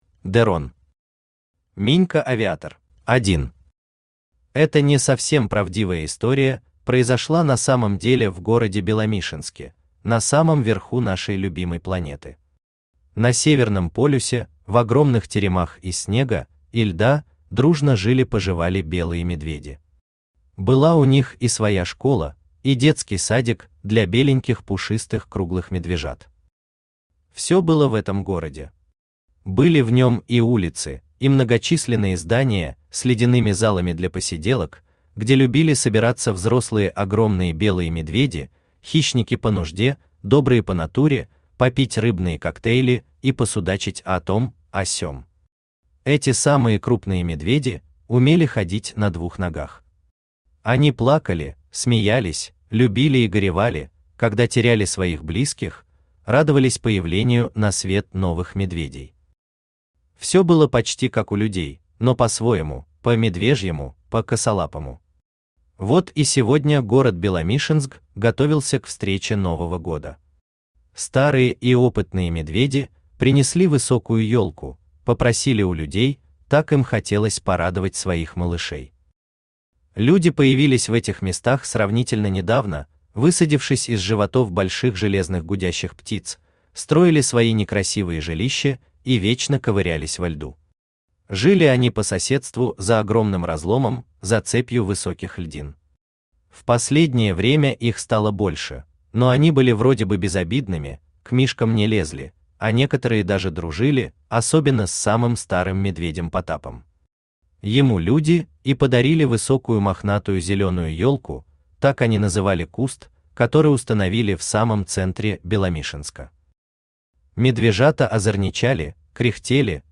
Аудиокнига Минька Авиатор | Библиотека аудиокниг
Aудиокнига Минька Авиатор Автор De Ron Читает аудиокнигу Авточтец ЛитРес.